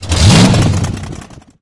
TurboChargeApplied.ogg